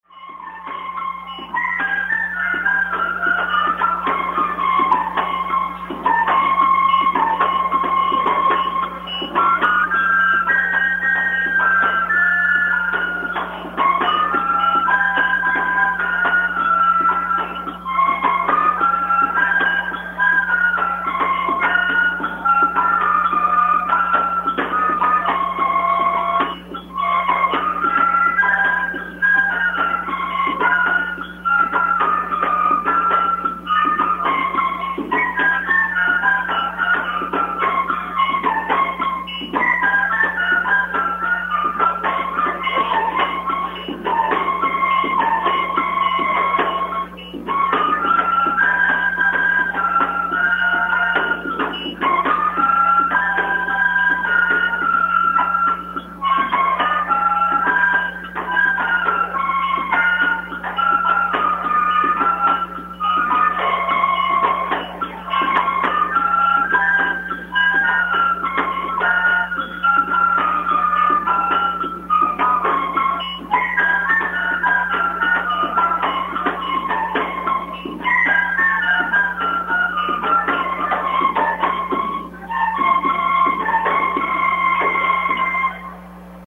PDE chega ao Ceará ao som de pífaros
Fortaleza - A cerimônia de apresentação das ações do Plano de Desenvolvimento da Educação (PDE) em Fortaleza ainda não havia começado, mas já se ouvia o som de pífaros, triângulo e zabumba, tocados por um grupo de crianças e adolescentes da cidade de Aquiraz.